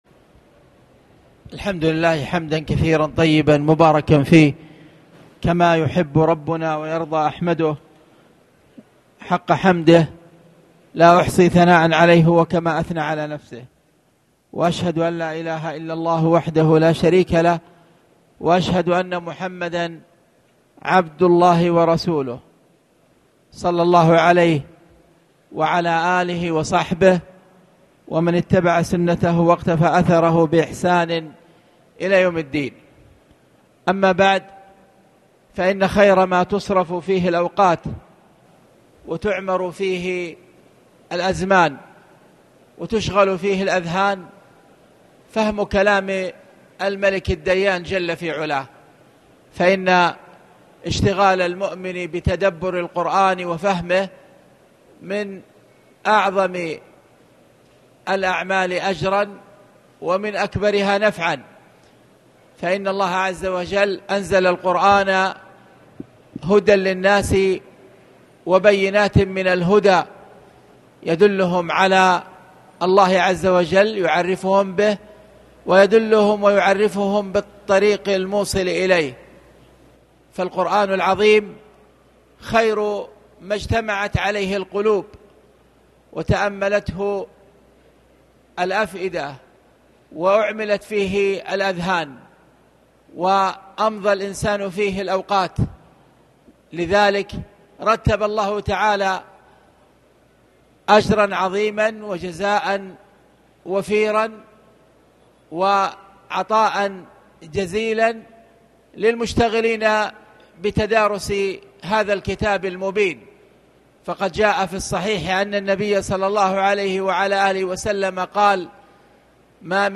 تاريخ النشر ٩ رمضان ١٤٣٨ هـ المكان: المسجد الحرام الشيخ